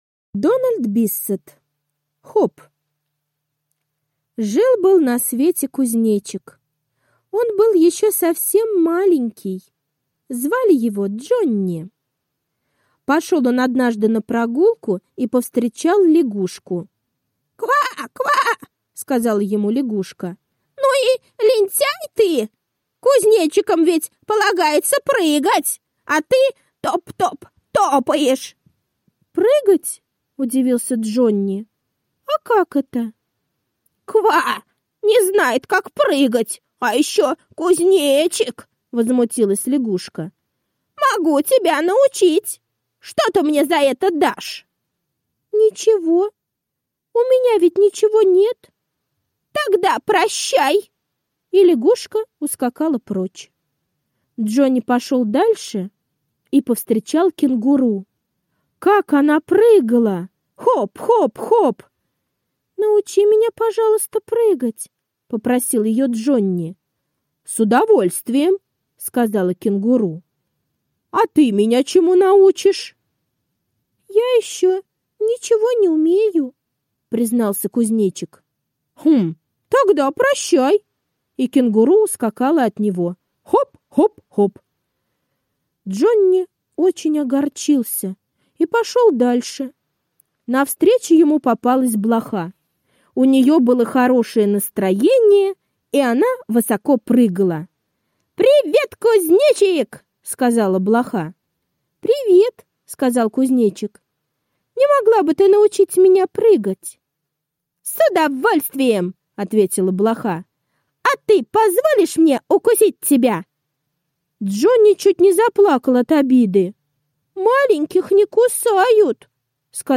Хоп! — аудиосказка Дональда Биссета. Сказка о том, как совсем маленький кузнечик Джонни научился прыгать.